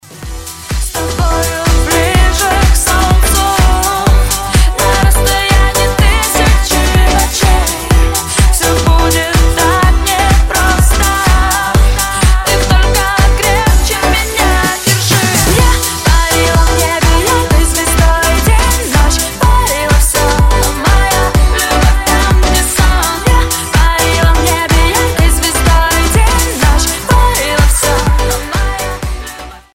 • Качество: 256, Stereo
dance
Club House
vocal